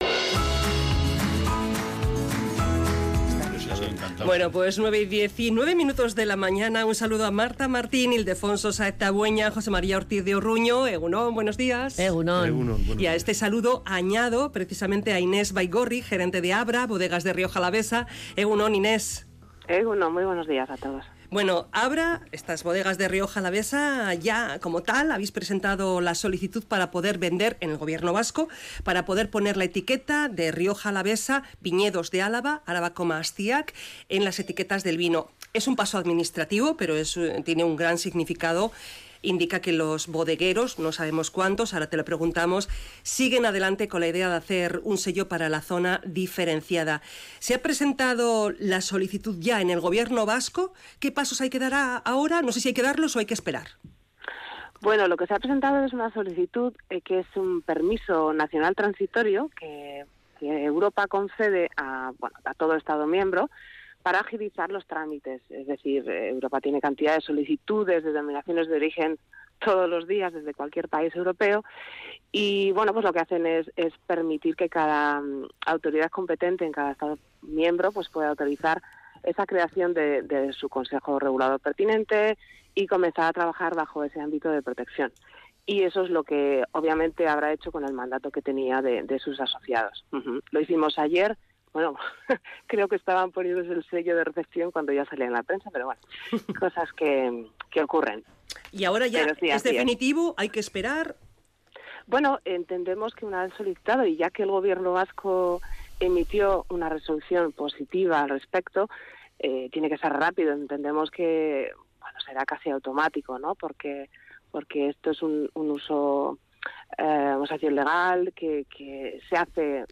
Entrevistada en Radio Vitoria